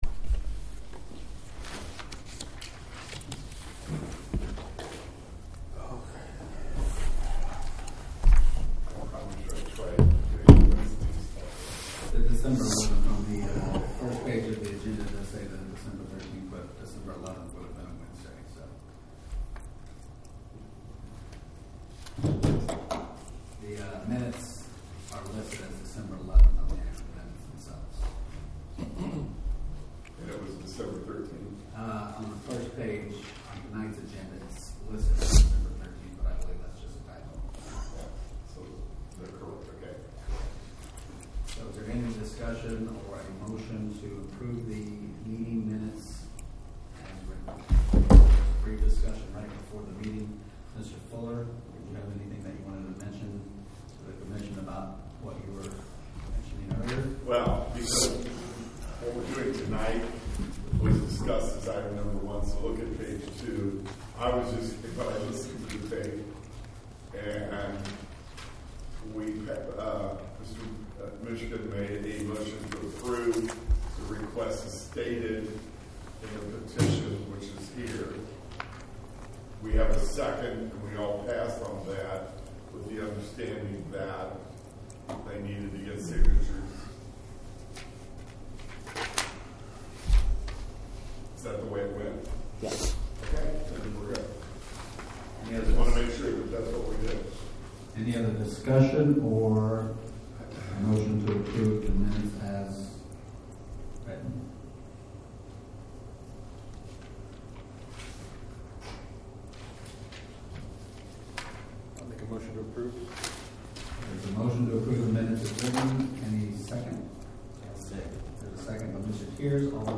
February 2020 Trafiic Commission Meeting mp3